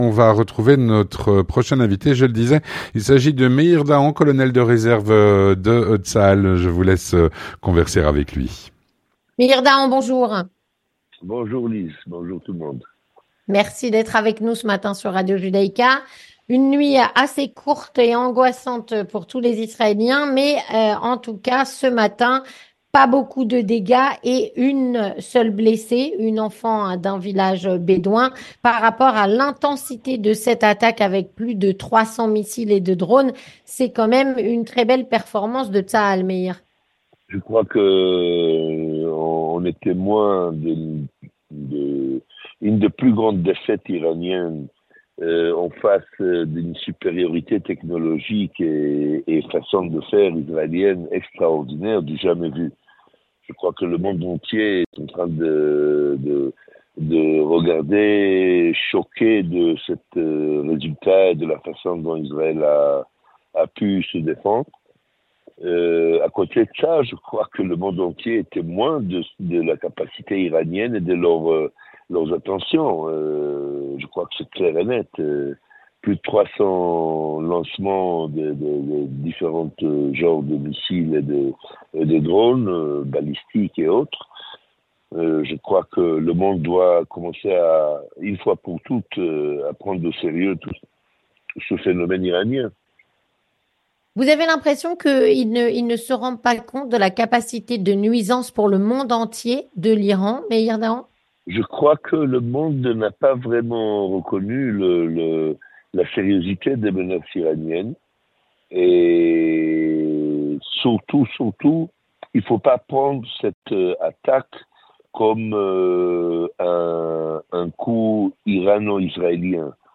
Colonel de Réserve de Tsahal